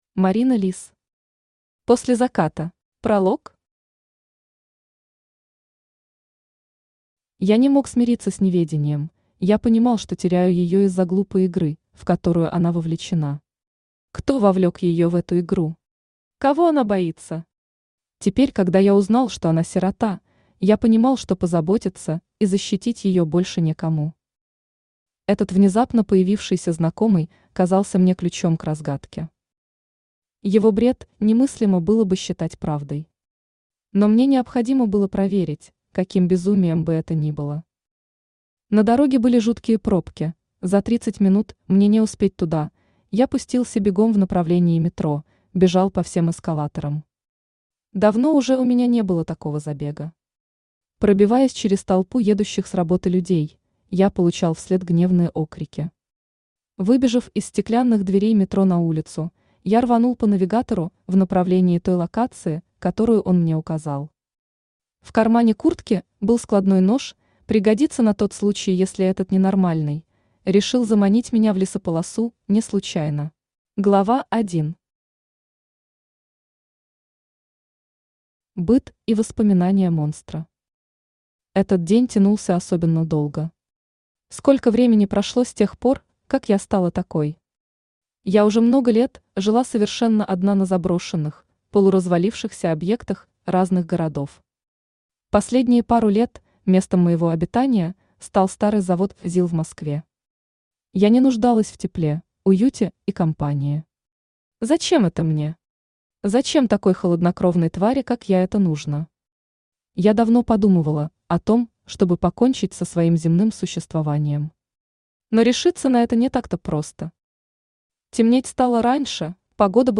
Аудиокнига После заката | Библиотека аудиокниг
Aудиокнига После заката Автор Марина Лис Читает аудиокнигу Авточтец ЛитРес.